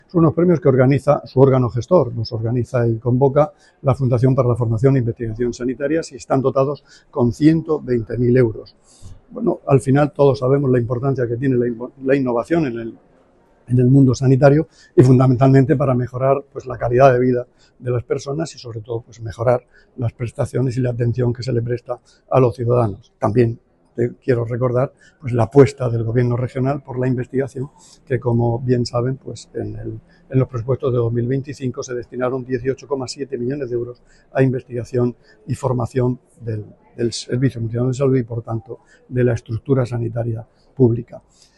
Declaraciones del consejero de Salud, Juan José Pedreño, sobre los premios de innovación en investigación.